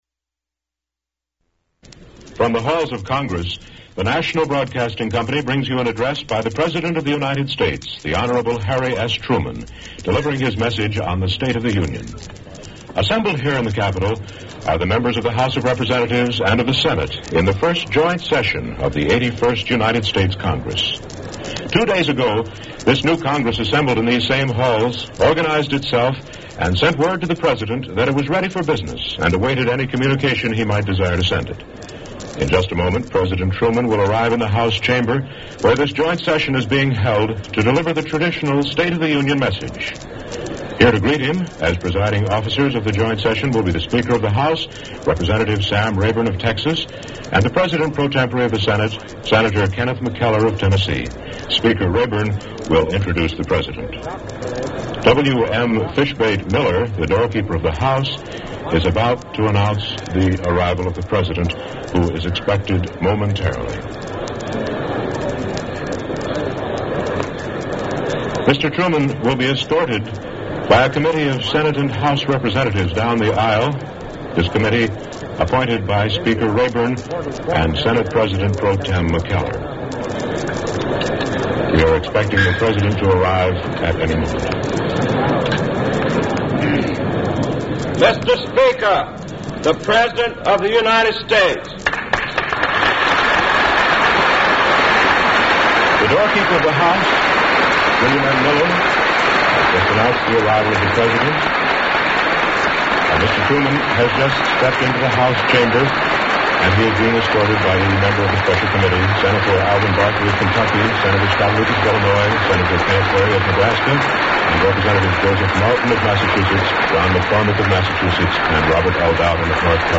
U.S. President Harry Truman delivers the State of the Union address
Harry Truman gives an address on the State of the Union, delivered to the first joint session of the 81st Congress in Washington, D.C.